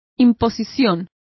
Also find out how imposición is pronounced correctly.